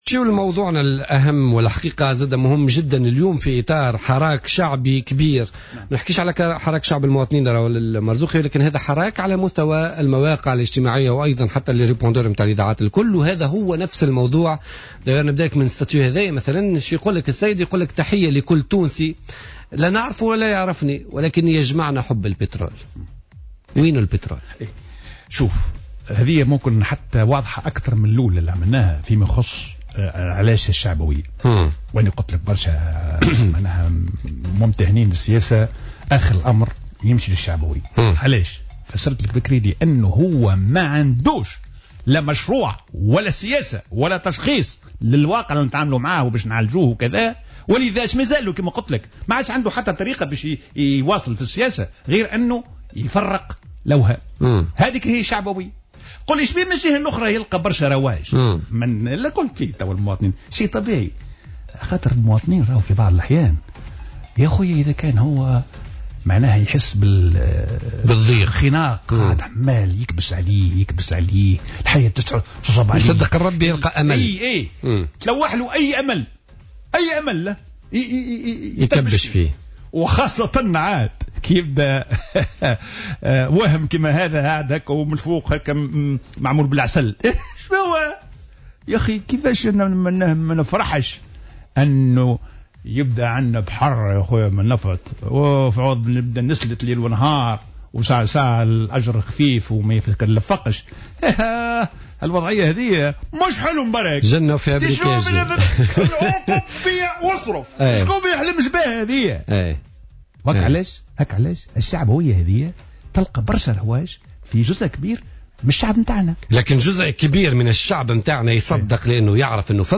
اعتبر وزير المالية الأسبق حسين الديماسي في برنامج "بوليتيكا" على "جوهرة أف أم" أن حملة "وينو البترول" هي مجرد "وهم" وحلم "شعبوي" لتحقيق مطالب وهمية.